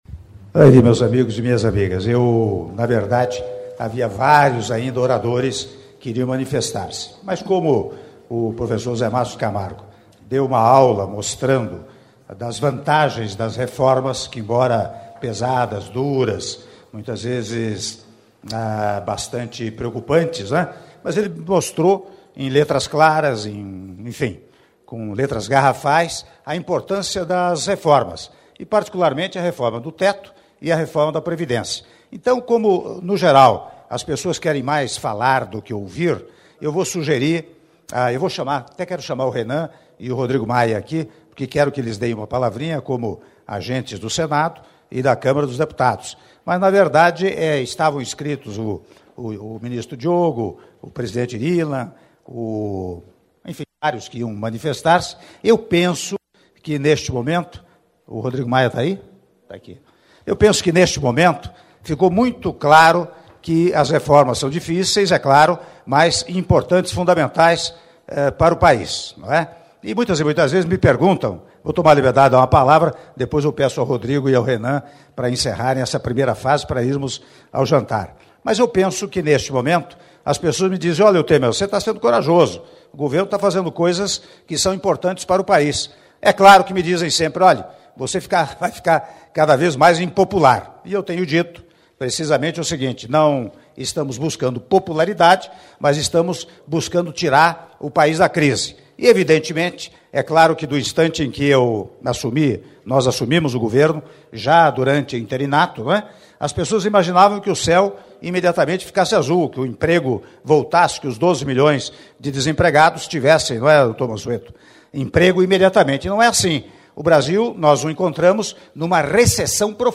Áudio do discurso do presidente da República, Michel Temer, duranta jantar com a base aliada no Senado - Brasília/DF (06min28s) — Biblioteca